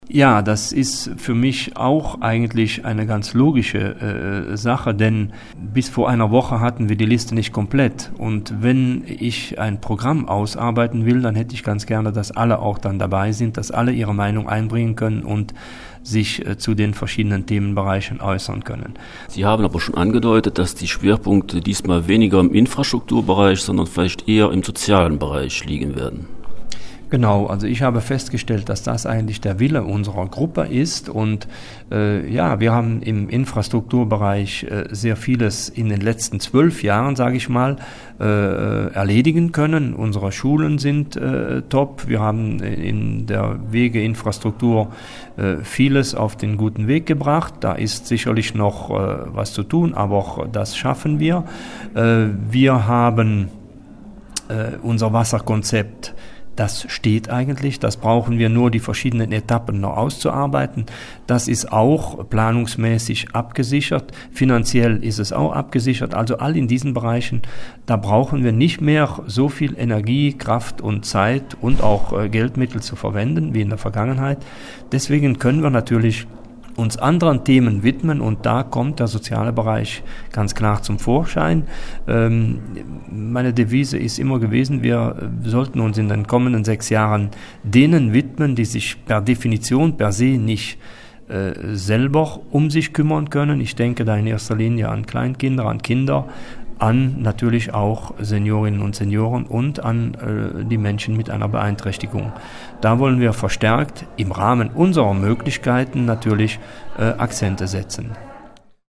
In der Gemeinde Büllingen wird sich erwartungsgemäß eine Einheitsliste bei den Gemeinderatswahlen am 14.Oktober präsentieren, nachdem sich die Liste Wirtz und die Freie Bürgerliste (FBB) zusammengeschlossen haben. Am Sonntag wurde die Liste im Hotel Tiefenbach in Büllingen vorgestellt.